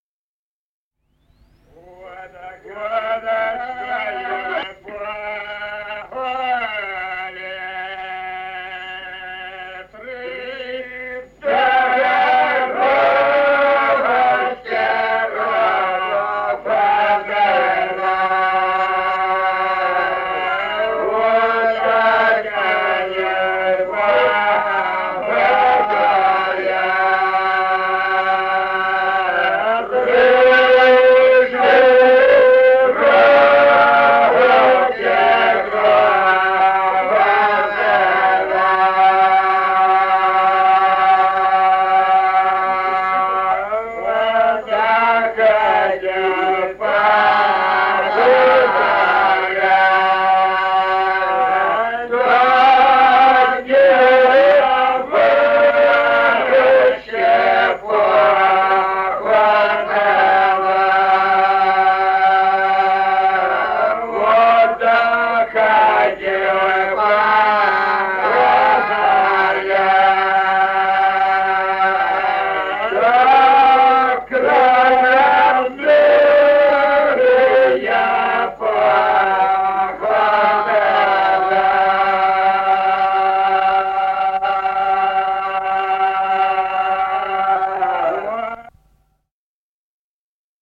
Песни села Остроглядово Вот да что и в поле.
Песни села Остроглядово в записях 1950-х годов